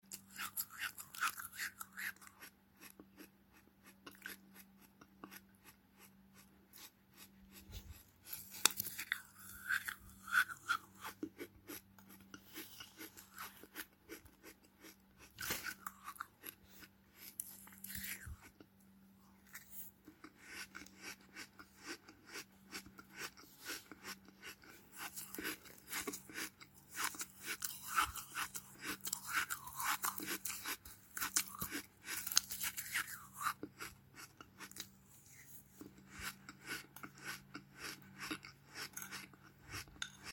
Sand Chewing ASMR | No Sound Effects Free Download